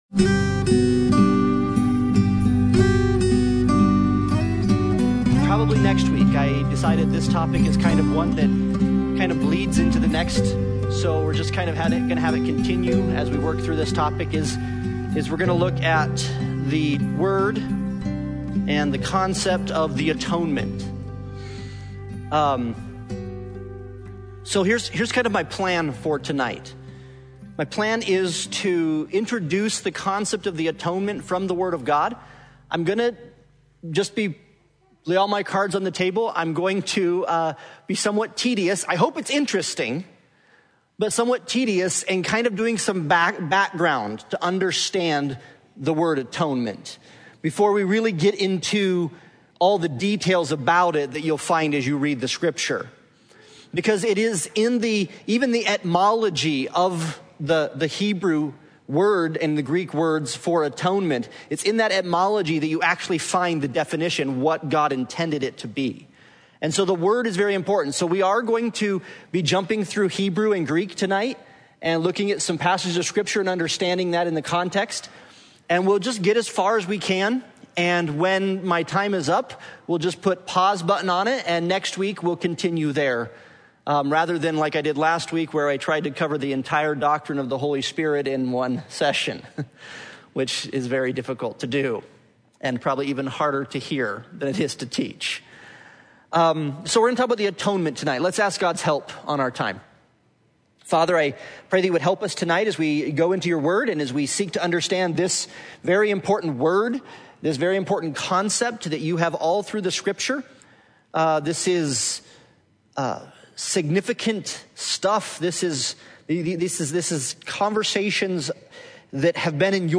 Passage: Romans 3:23-36 Service Type: Sunday Bible Study « Jesus Prays for Us